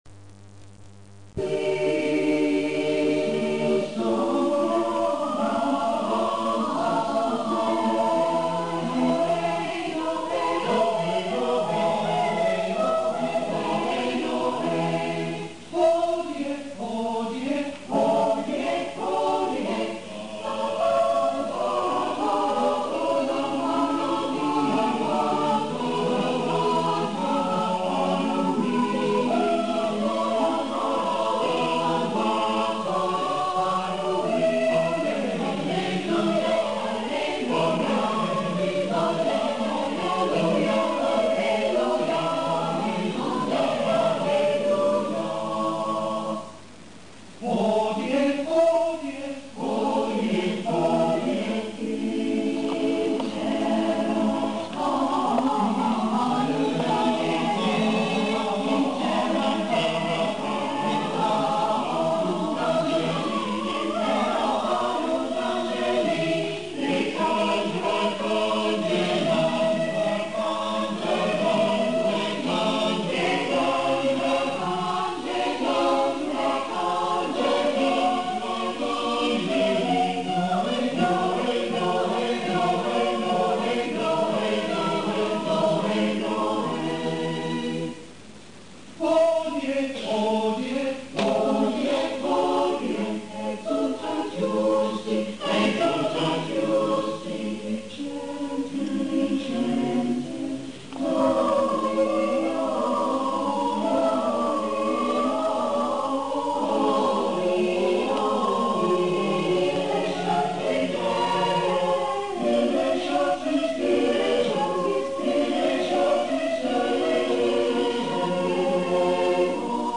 Ashbourne Senior Choir Recordings
Play image ... 2 Mins 50 Seconds (167 KB 8 Kbits/second 11,025 Hz), recorded at rehearsals with an approx. 60 second loading time (at about 3 KB/second).